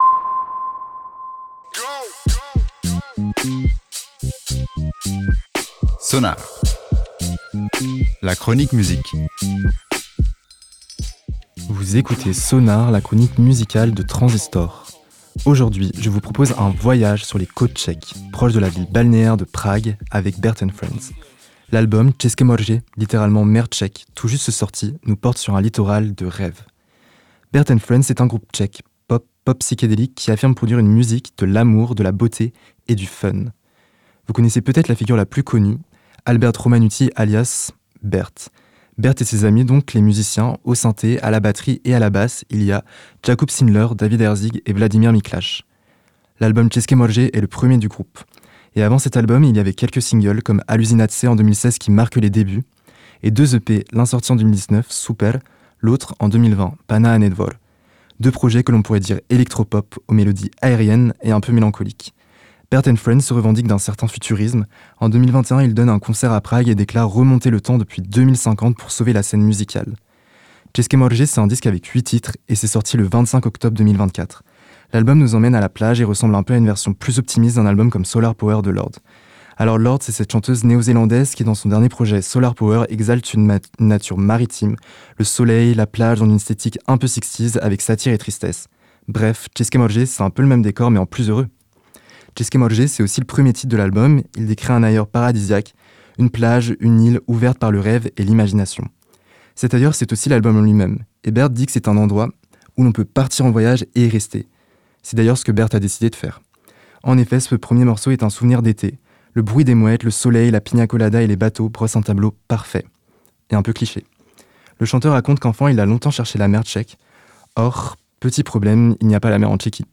Le disque a huit titres, est pop-psychédélique et l'ambiance solaire, éthérée, parfois un peu mélancolique.